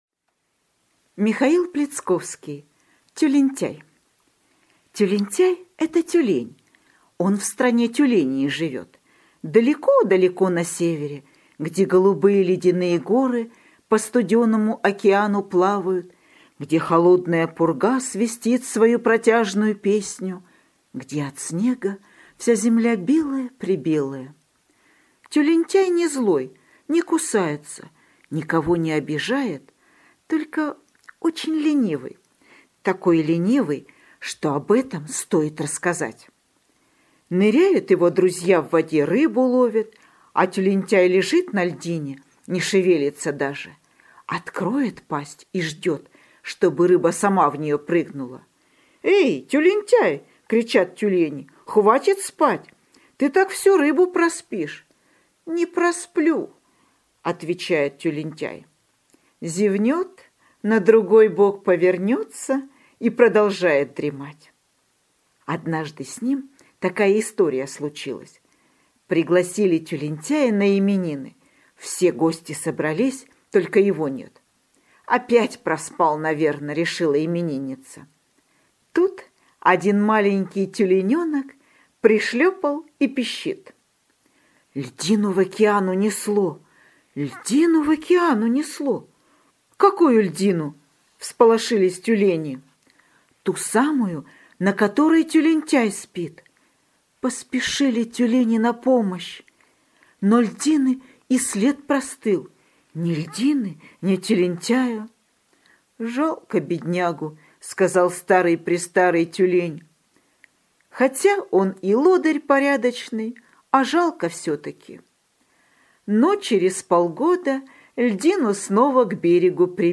Аудиосказка «Тюлентяй»